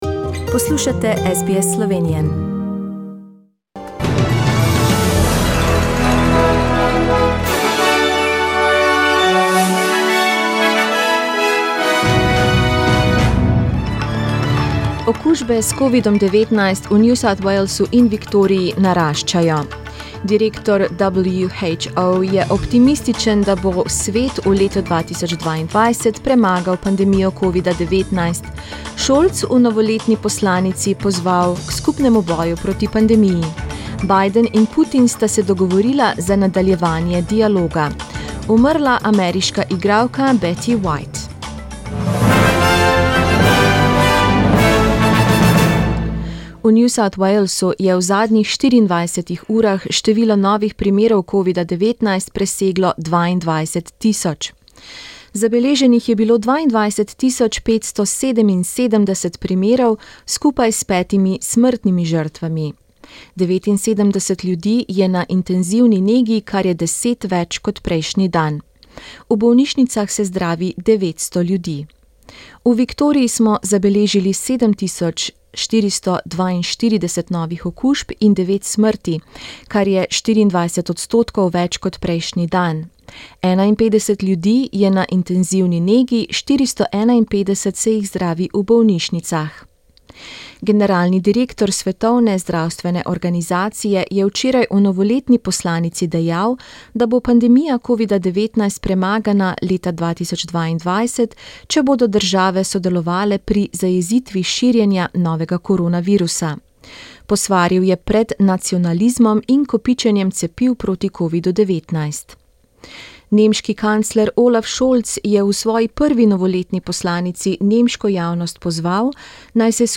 Poročila v slovenskem jeziku 1.januarja